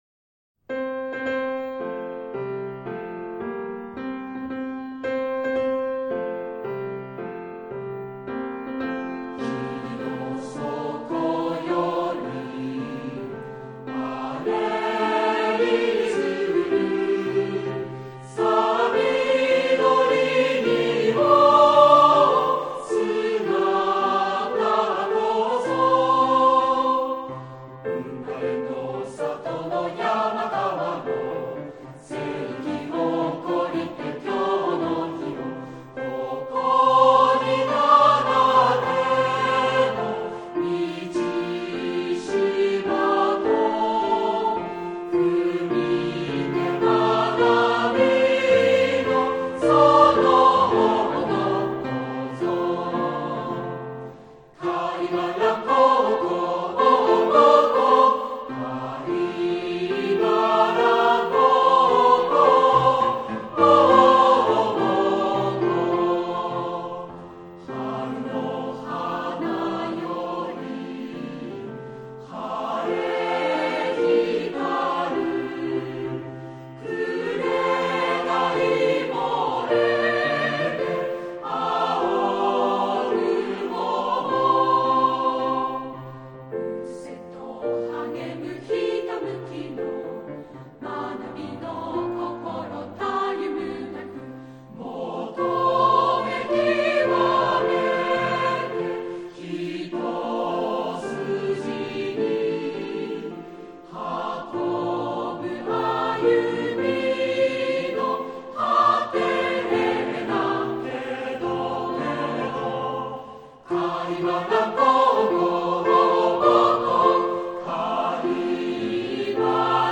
Koka-korasu.wav